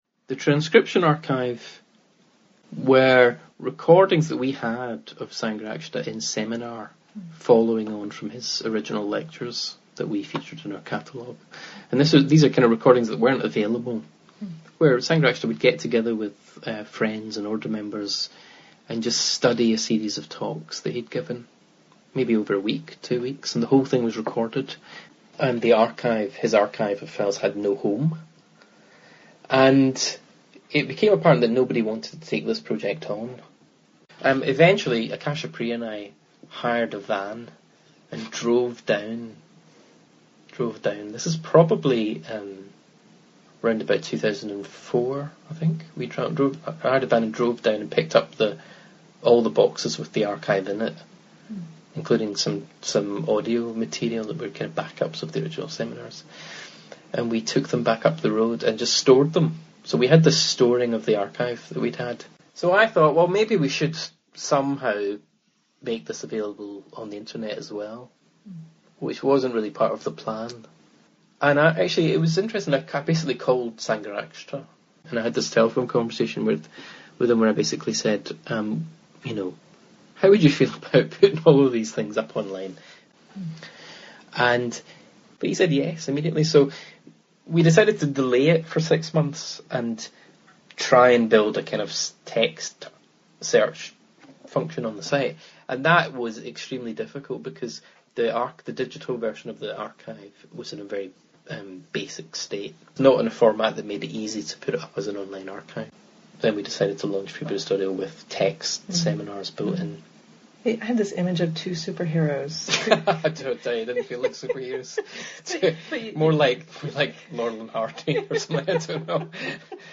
Philosophy Buddhist Society Religion Buddhist Centre